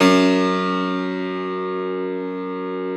53e-pno04-F0.wav